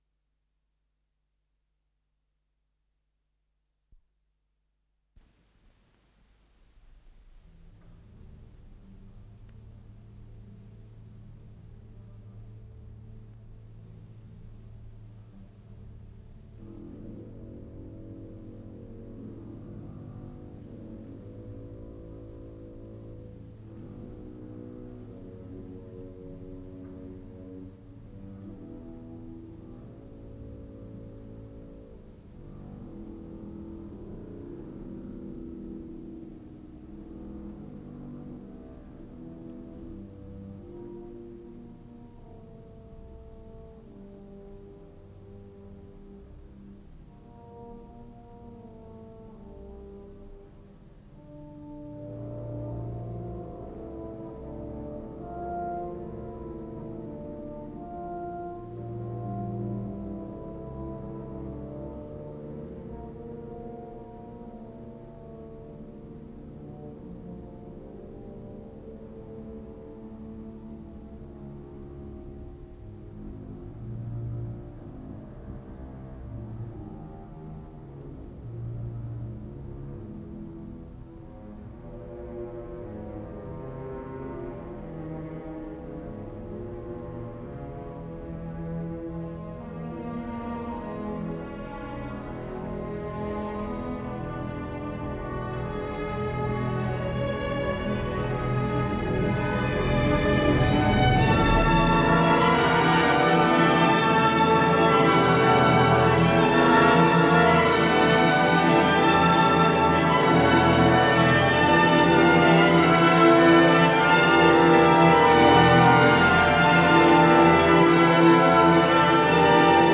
Исполнитель: Андрей Гаврилов - фортепиано
Название передачи Концерт для фортепиано с оркестром, соч. 1929-31гг. Подзаголовок Для левой руки, одночастный, ре минор - ре мажор Код ПКС-018479 Фонд Норильская студия телевидения (ГДРЗ) Редакция Музыкальная Общее звучание 00:17:48 Дата записи 1978 Дата переписи 24.12.1981 Дата добавления 06.06.2022 Прослушать